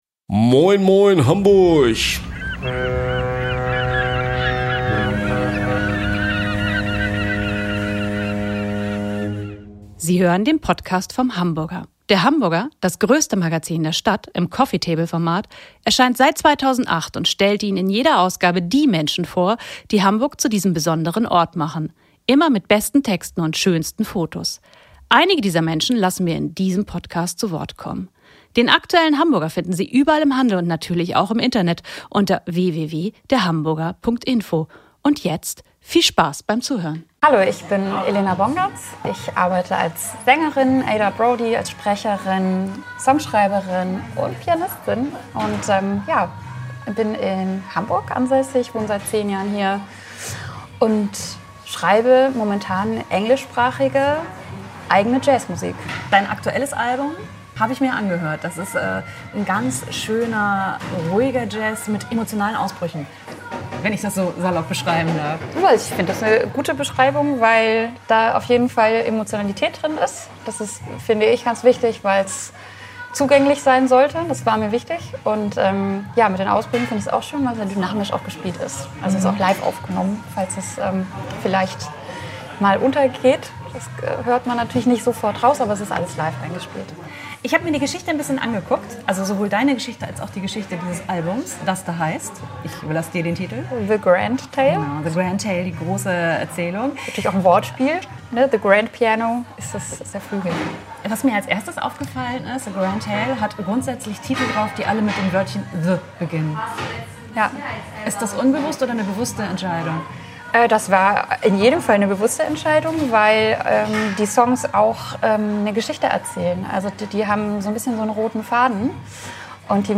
Ein Gespräch über den Mut, eigene Wege zu gehen, über Rückschläge, Erfolge und die Geschwisterliebe.